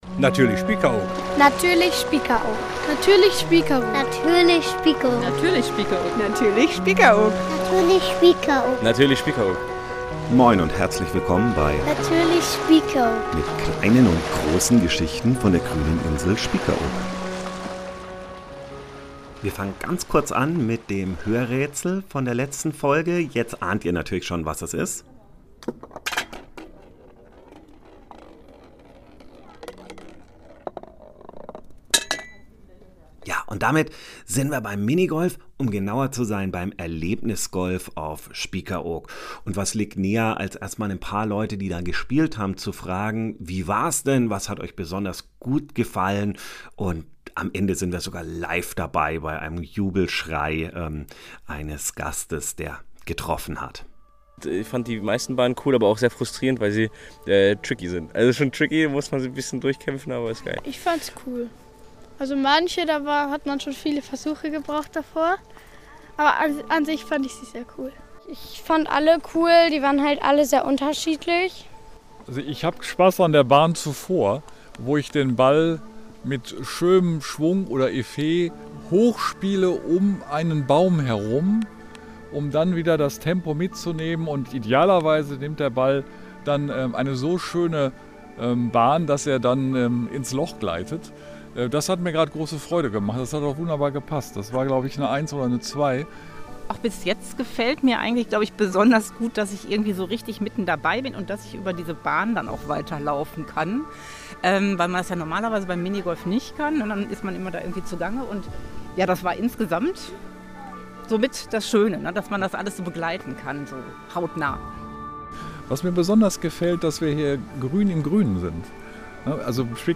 Zum Ende der Folge sind wir live